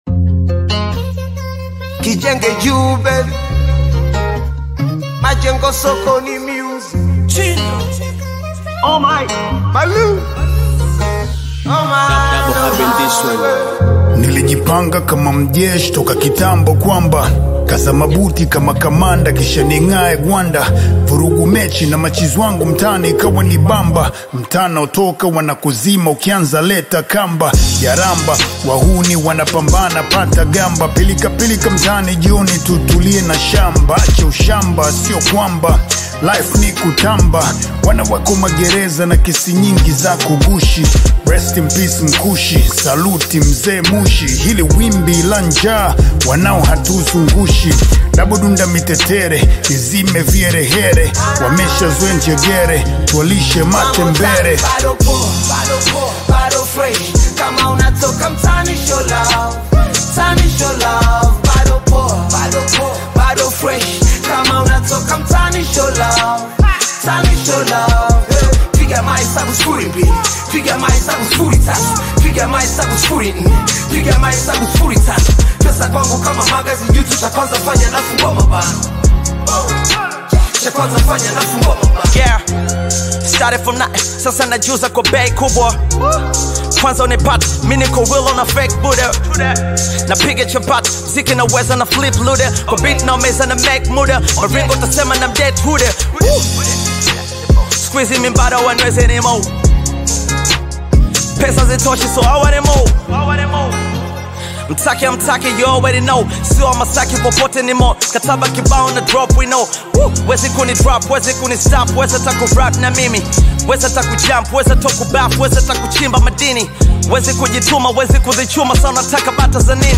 rapper
Bongo Flava